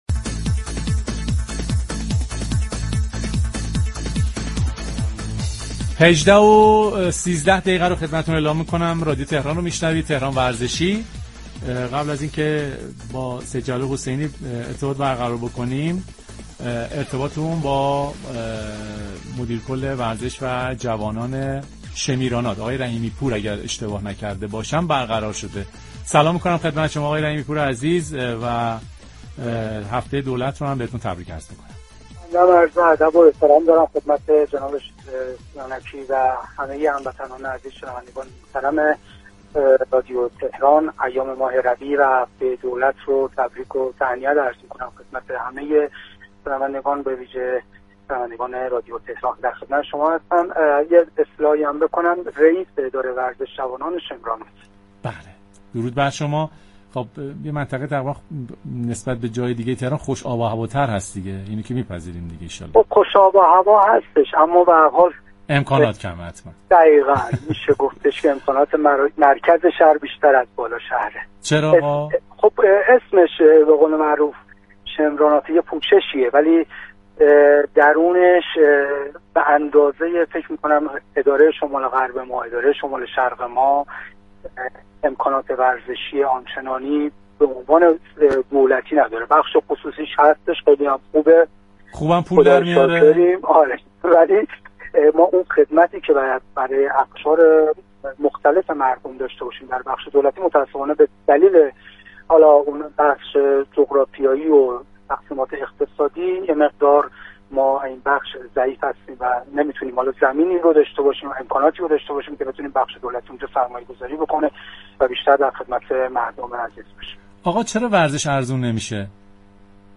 رئیس اداره ورزش و جوانان شمیرانات در گفت‌و‌گو با رادیو تهران درباره وضعیت ورزش این منطقه گفت: با وجود هوای خوب، امكانات دولتی ورزشی كم است اما رقابت‌های استعدادهای برتر ورزشی، فرصتی برای كشف و پرورش ورزشكاران پایه است و باعث شده ورزشكاران شمیرانات در رشته‌هایی مثل بوكس، تكواندو، شنا و وزنه‌برداری سال جاری، مدال‌هایی كسب كنند.